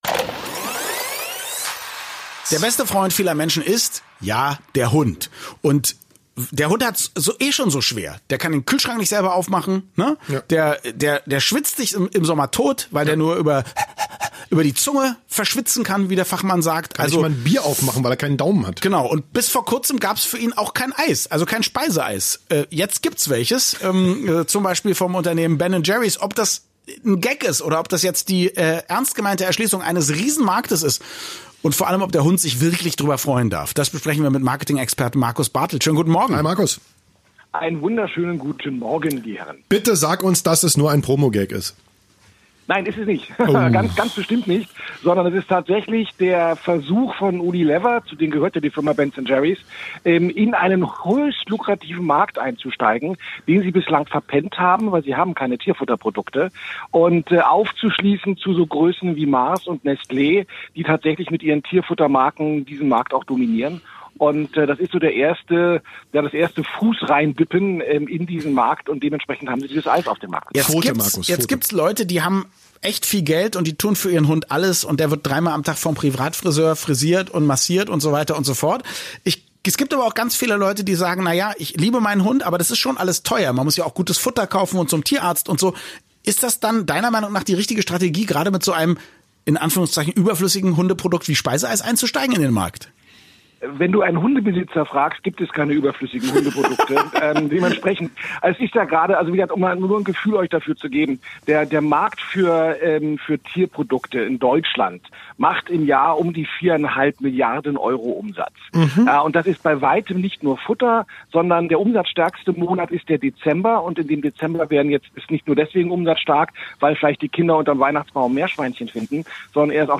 Die Radio-Interviews seit 2010